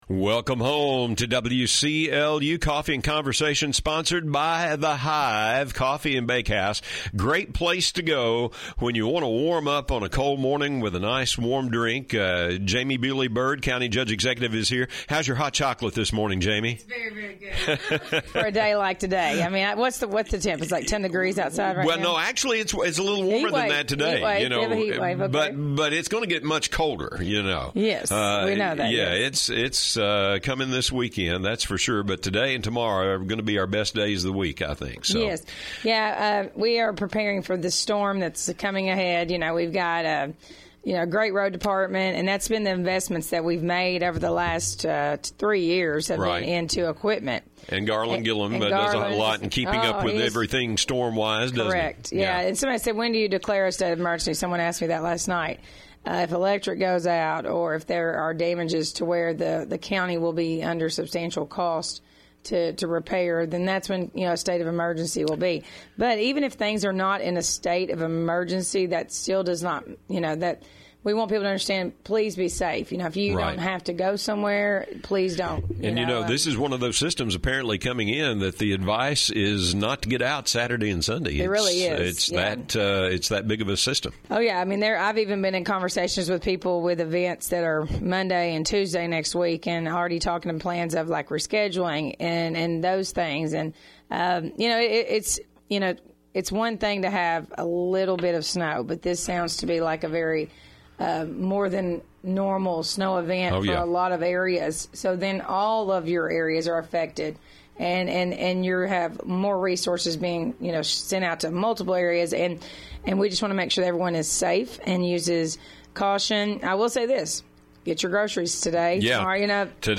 AUDIO: Barren County judge-executive talks about new animal control ordinance, area industry
barren-county-judge-executive-talks-about-new-animal-control-ordinance-area-industry.mp3